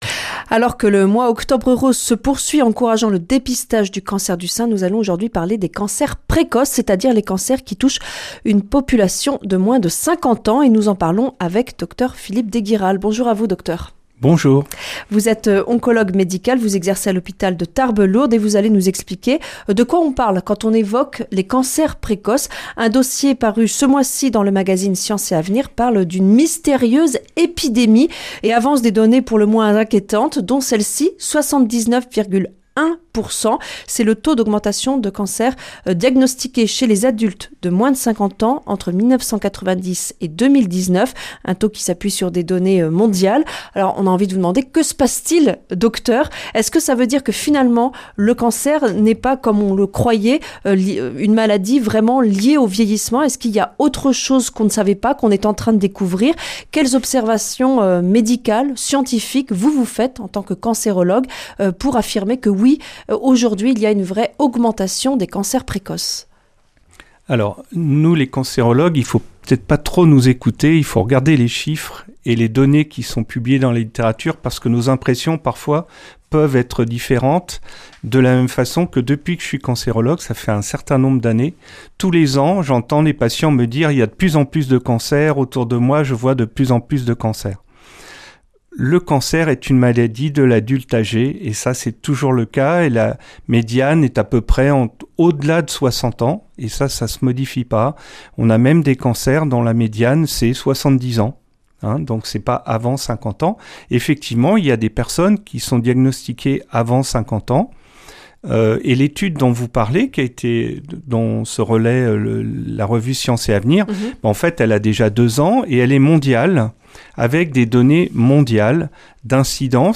Accueil \ Emissions \ Information \ Locale \ Interview et reportage \ Cancers précoces : faut-il s'alarmer ?